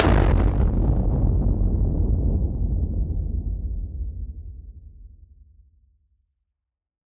BF_SynthBomb_A-03.wav